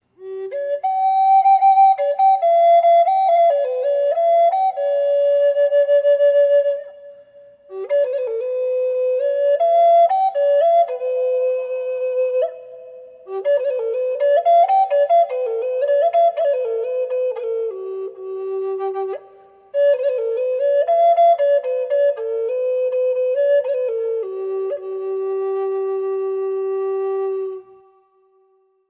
key of G & G#